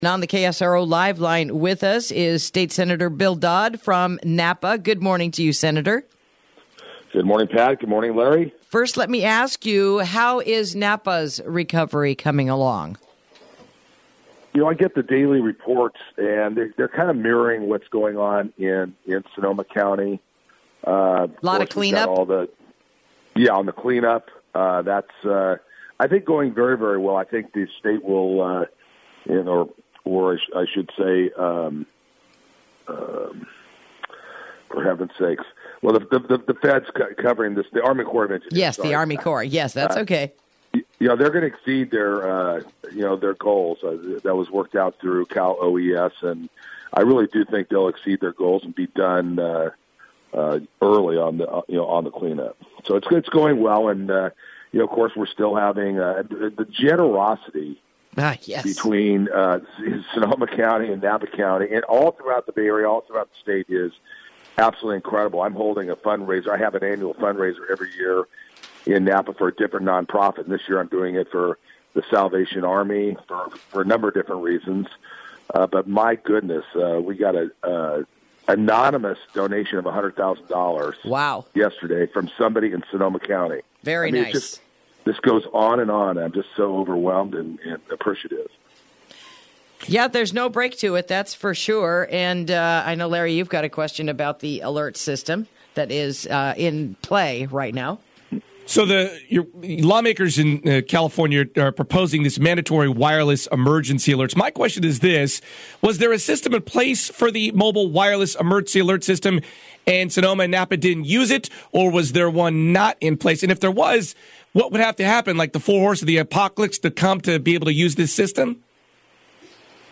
Interview: Bill for the Wireless Alert System
State Senator Bill Dodd, joins us to talk about the bill he is co-authoring for a wireless alert system.